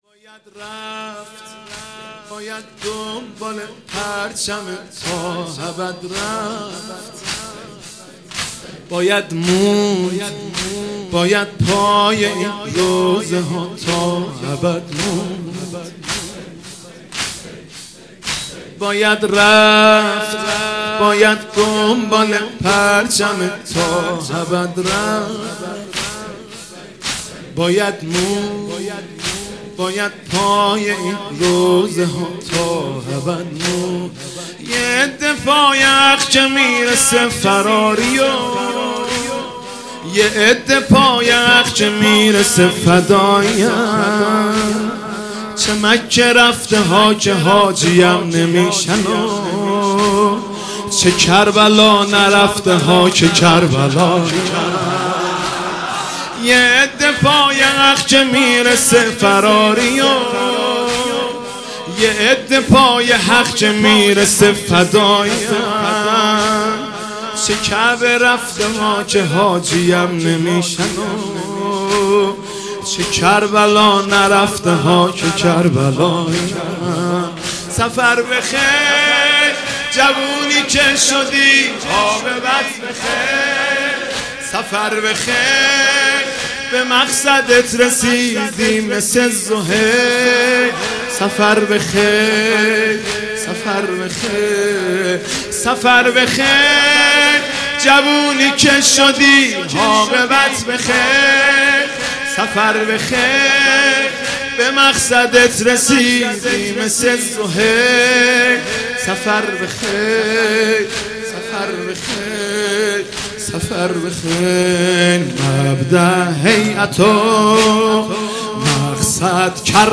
این مداحی را مهدی رسولی خوانده است.
مداحی ناب